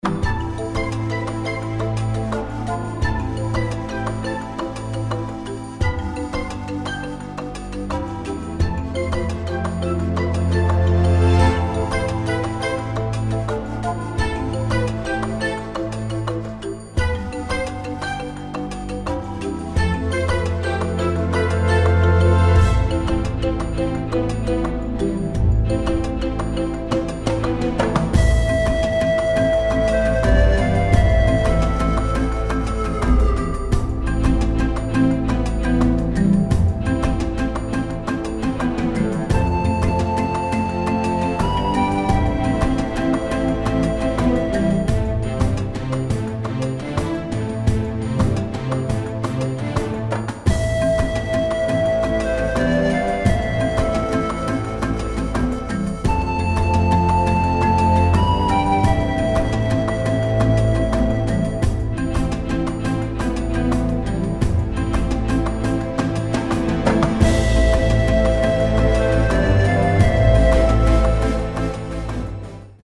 Buoyant, dramatic and heartfelt